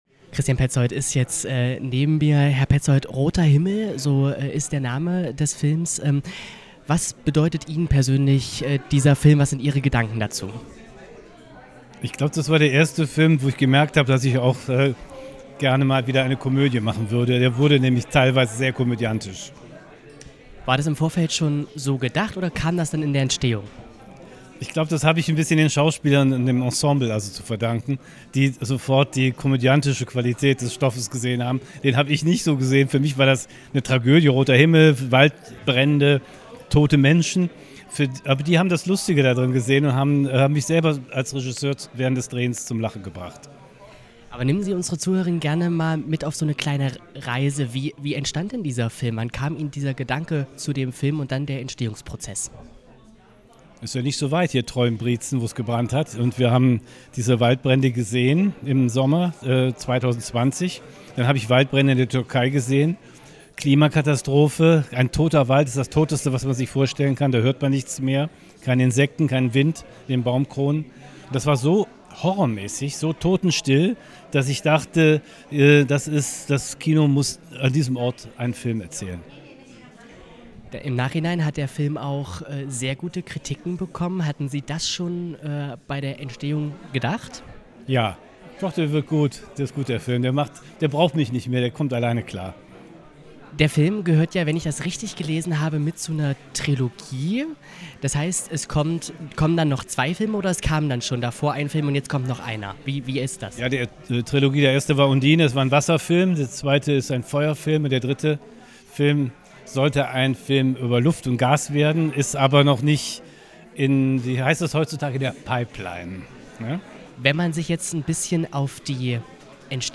„Roter Himmel“: Regisseur Christian Petzold im Interview – h²radio